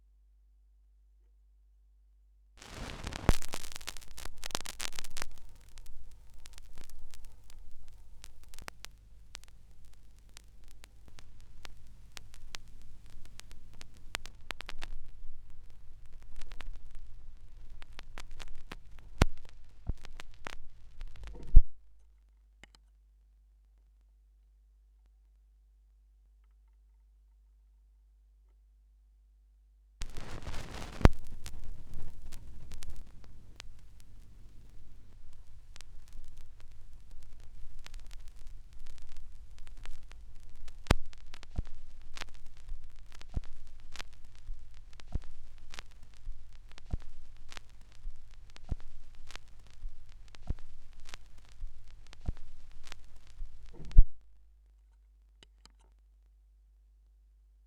2017 Schallplattengeräusche (3).m3u